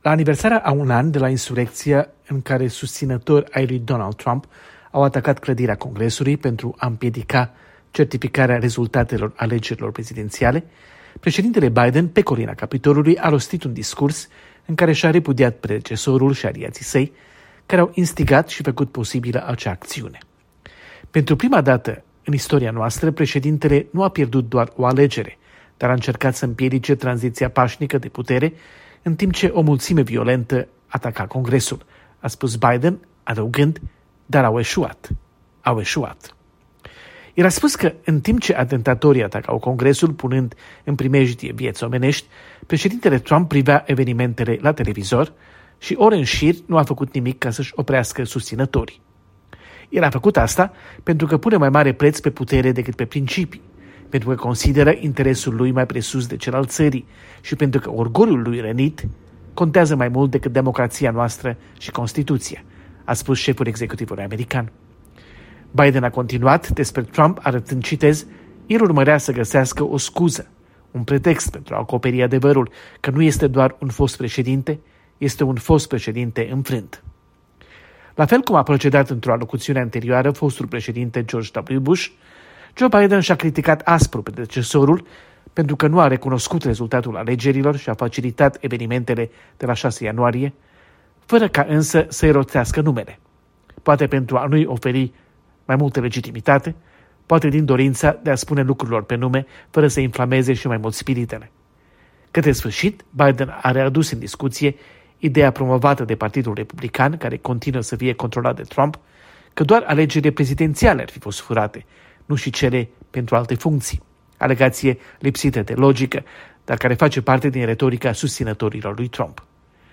Corespondență de la Washington